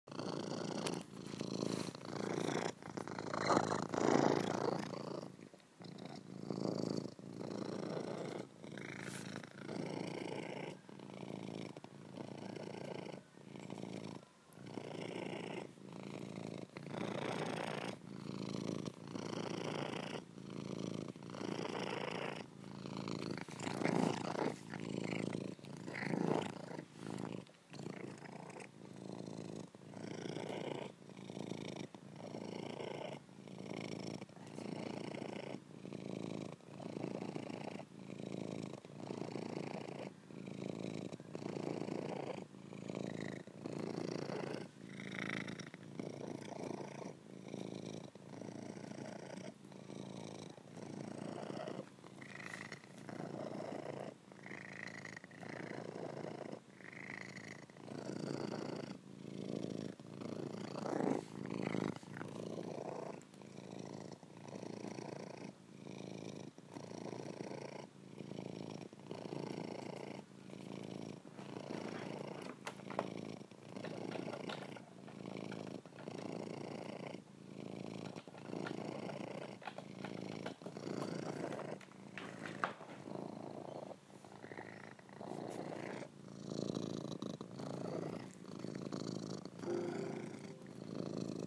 HerbiePurr.mp3